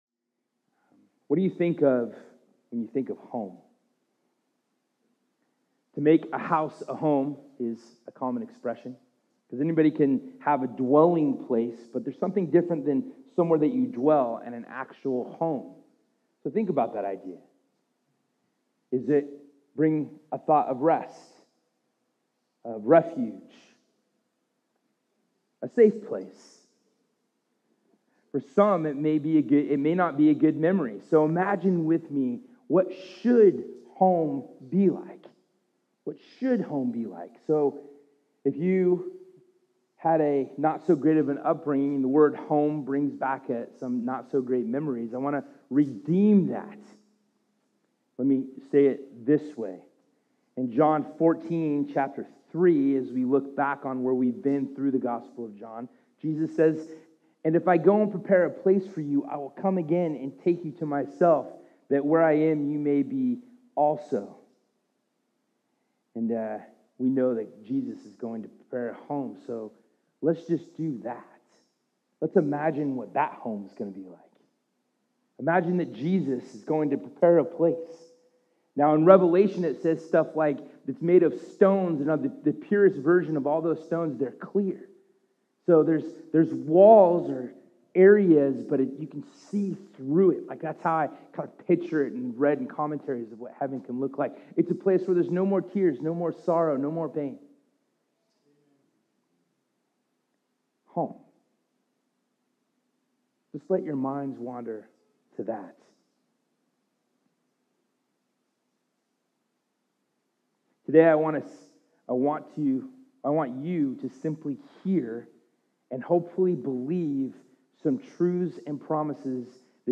“Home” Sermon NotesDownload Sunday Service We are stoked that you are here!